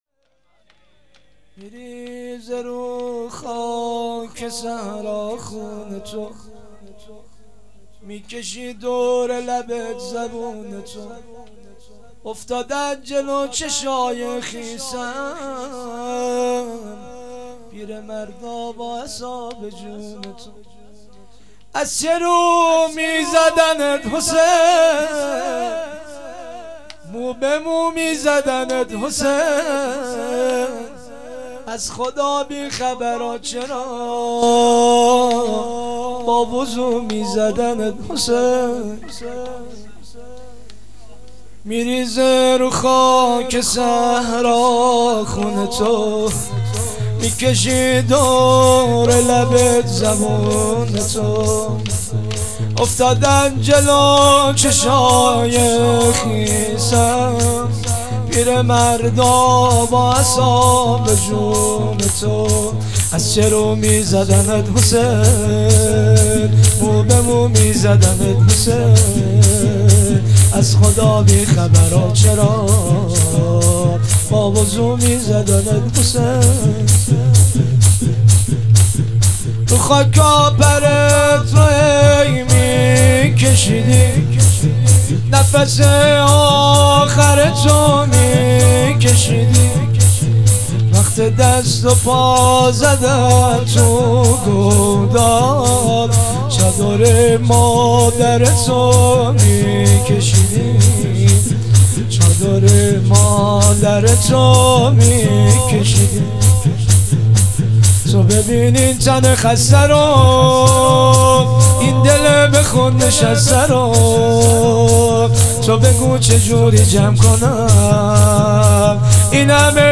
دهه دوم محرم ۹۸.شب اول